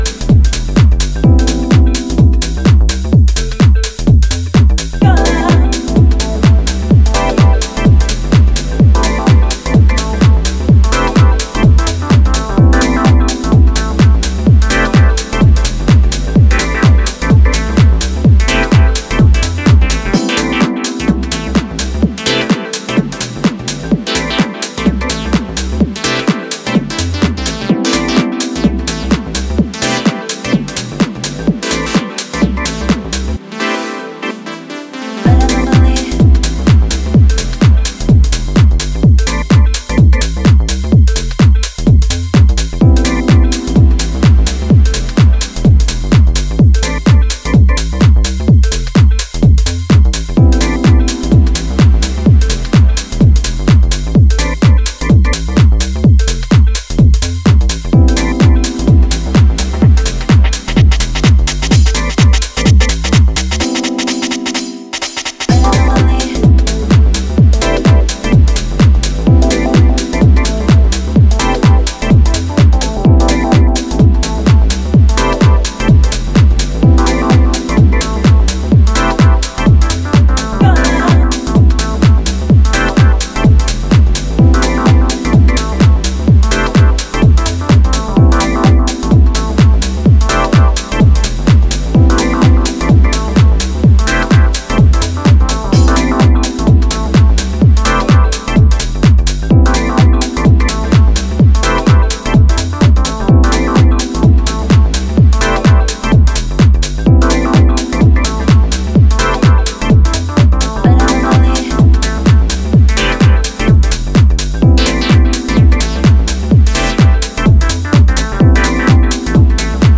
Styl: House, Techno